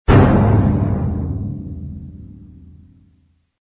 game_over.mp3